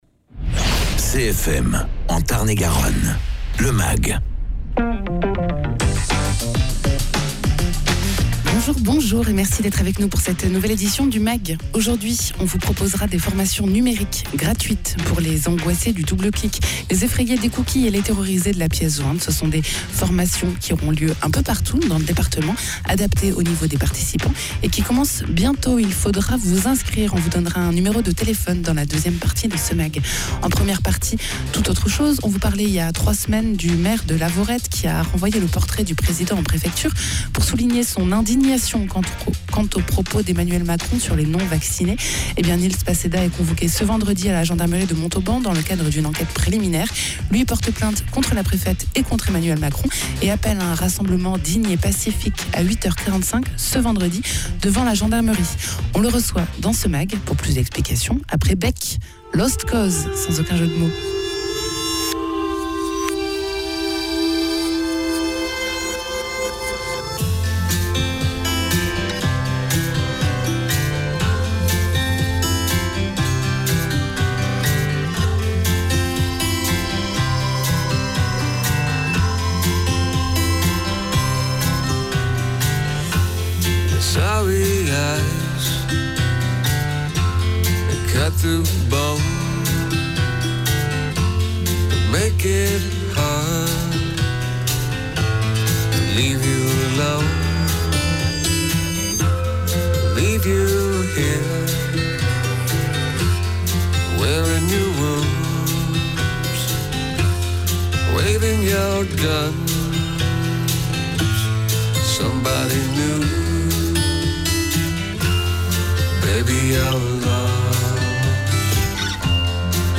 Invité(s) : Nils Passedat, maire de Lavaurette